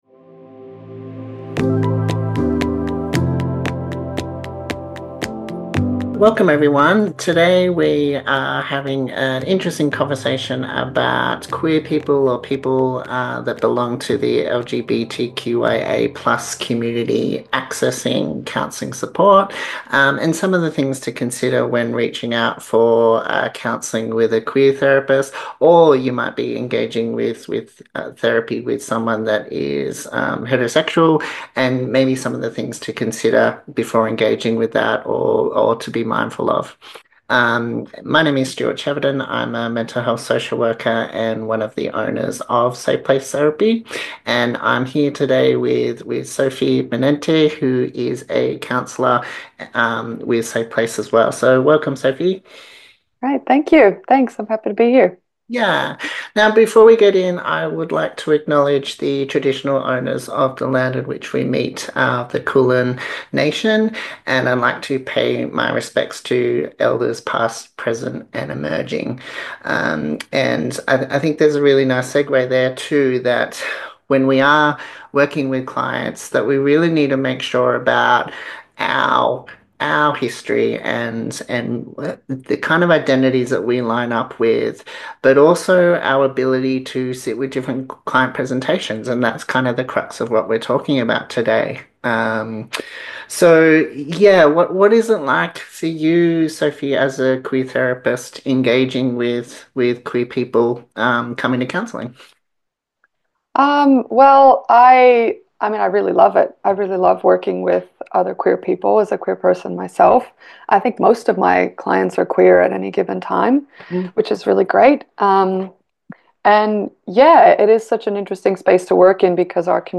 Queer therapists talk about how to find the best queer counsellor for you – someone who will make you feel seen and heard.